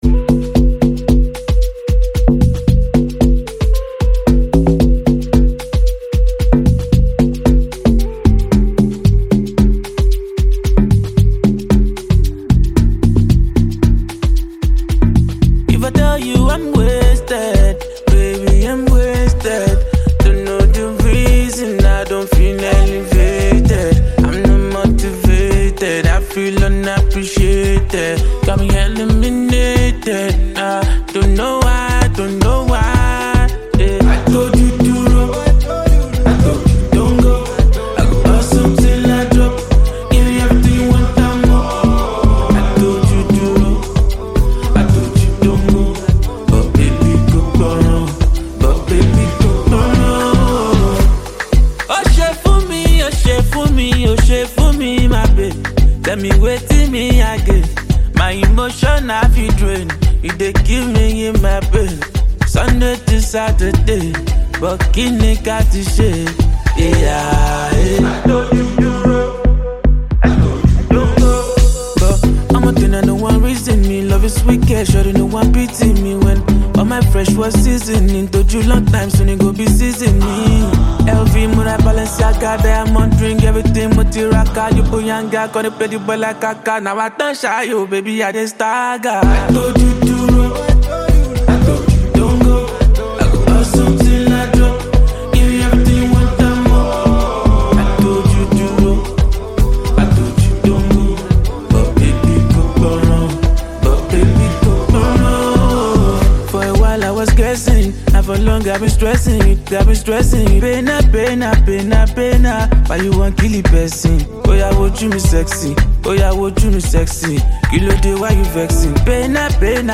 Nigeria Music
soothing yet captivating track
mood-boosting single